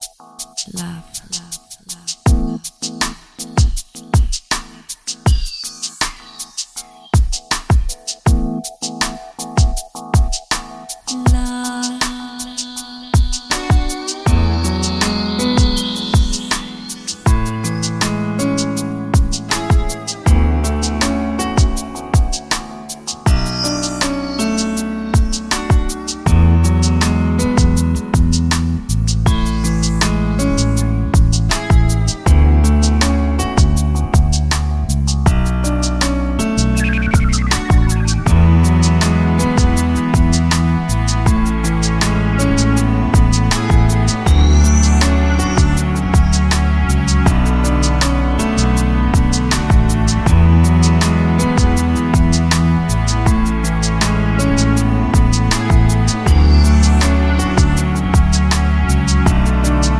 Электронная
Ремиксы в стиле chill-out на такие известные песни